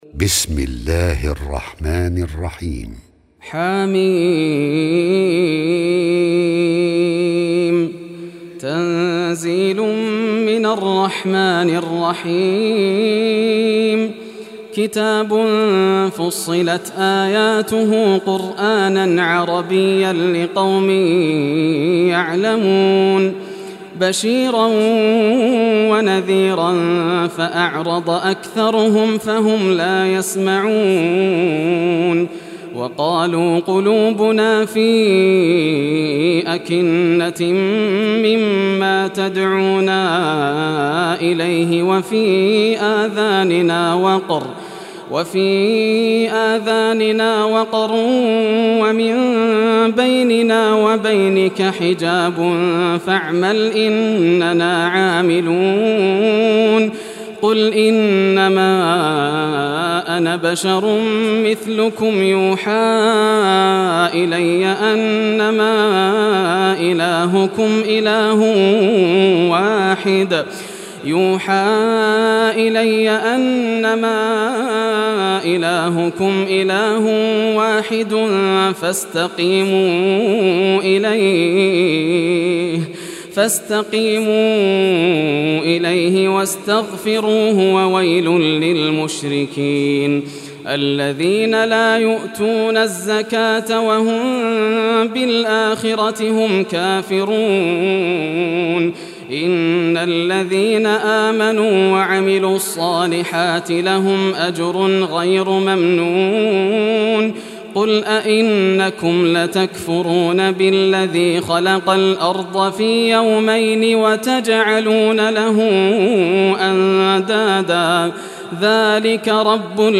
Surah Fussilat Recitation Sheikh Yasser al Dosari
Surah Fussilat, listen or play online mp3 tilawat / recitation in Arabic in the beautiful voice of Sheikh Yasser al Dosari.